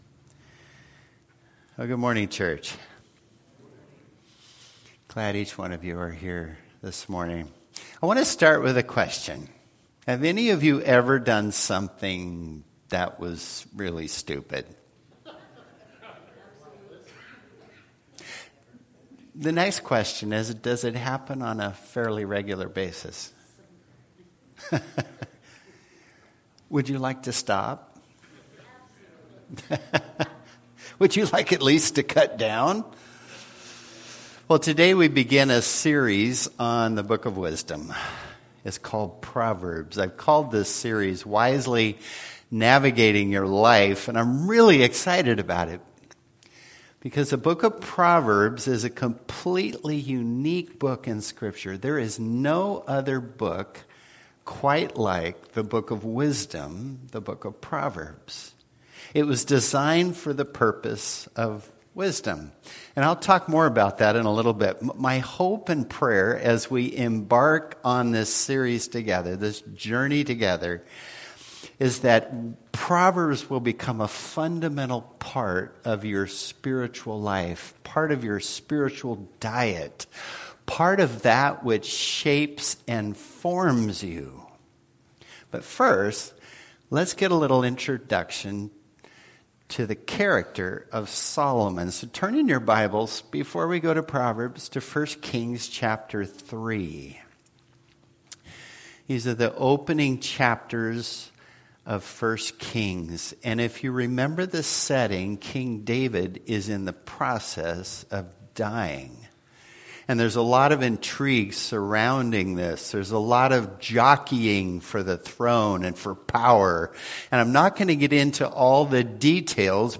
Passage: Proverbs 1:1-7 Service Type: Worship Service